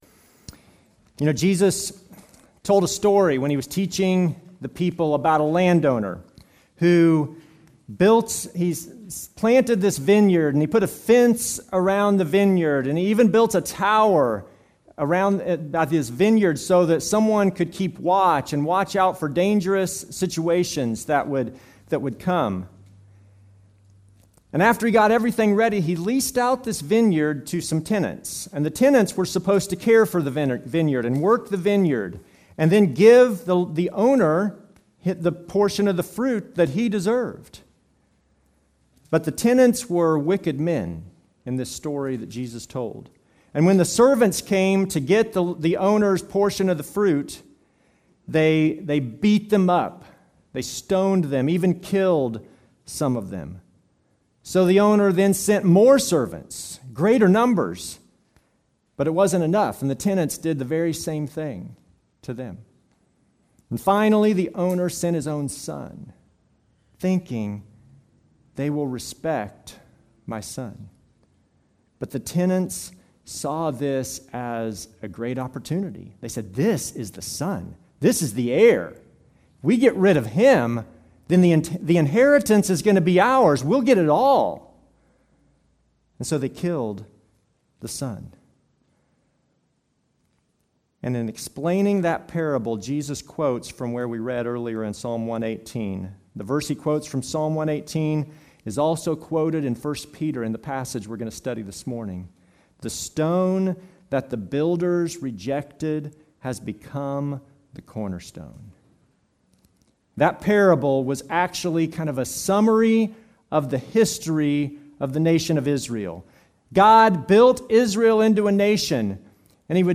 Service Type: Normal service